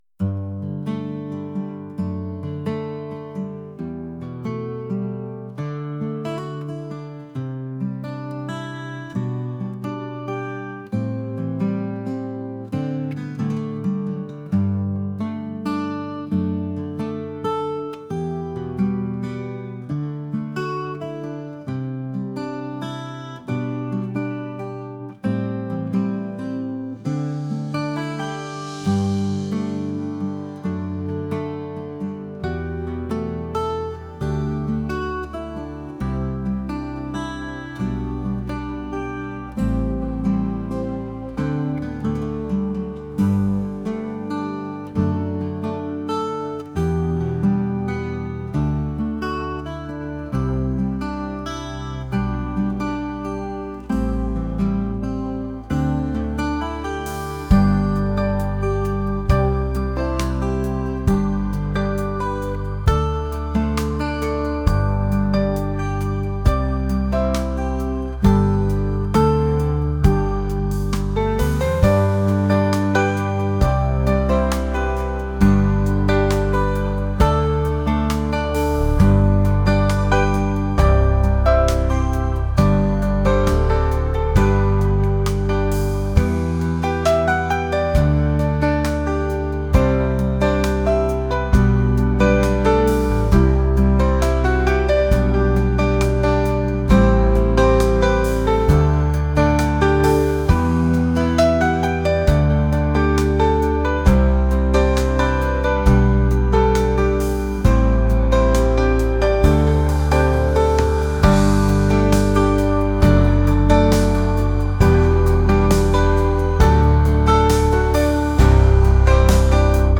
acoustic | pop | classical